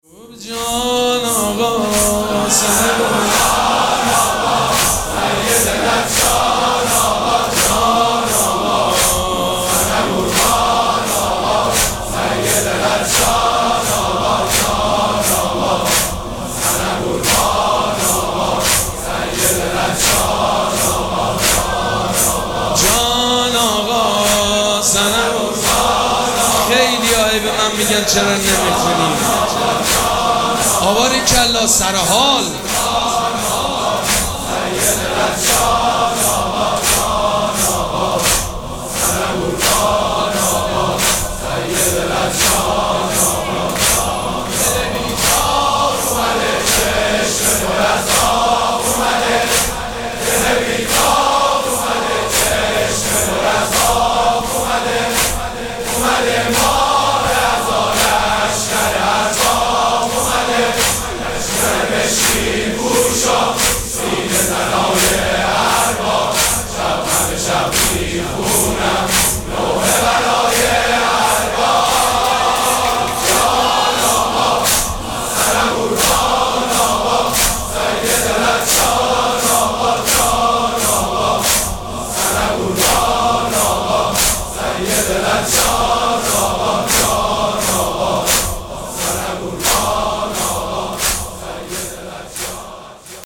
مراسم عزاداری شب چهارم محرم الحرام ۱۴۴۷
مداح
حاج سید مجید بنی فاطمه